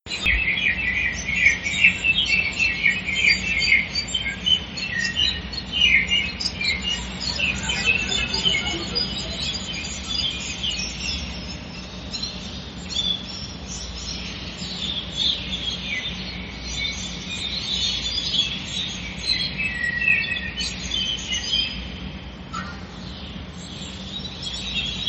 住在新北大都會，每日清晨五點多自然醒來，就能聆聽窗外的天然鳥叫聲，真是幸運。
晚上睡覺無須冷氣，享受開窗自然涼風與清晨自然鳥叫聲，開啟一天滿滿活力。